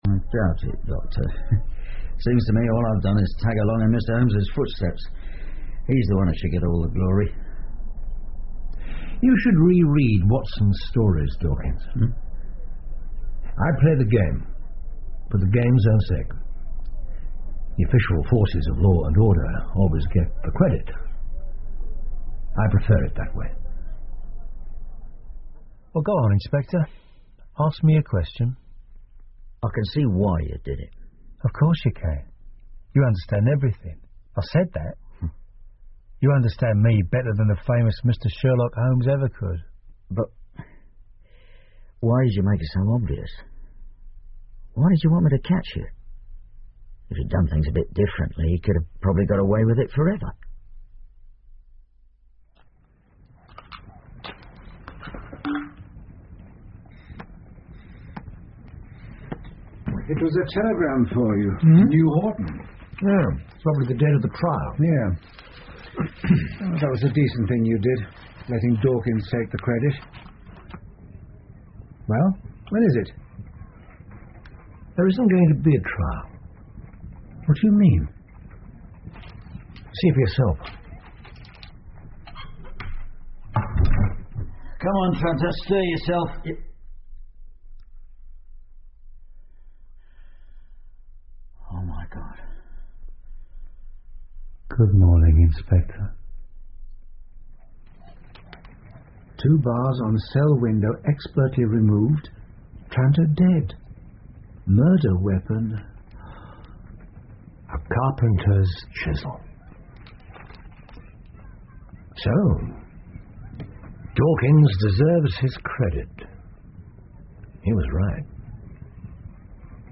福尔摩斯广播剧 The Shameful Betrayal Of Miss Emily Smith 9 听力文件下载—在线英语听力室